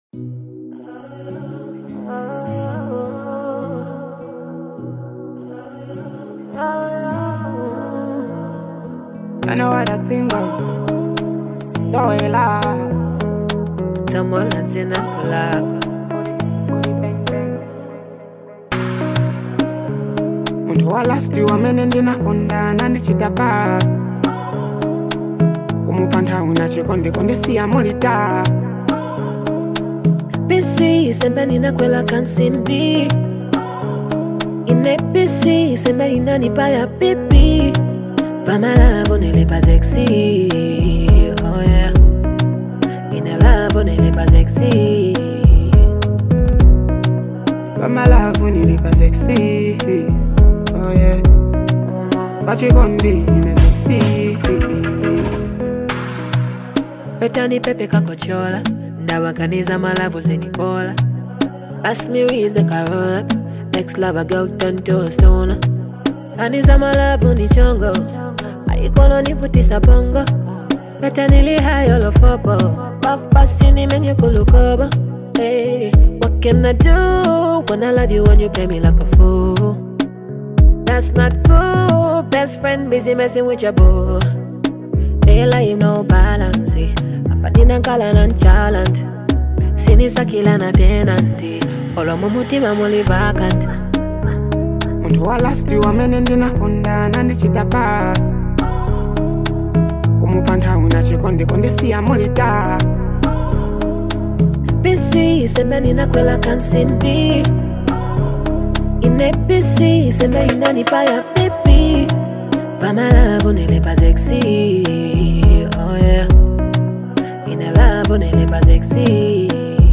with fans praising its infectious beat and catchy lyrics.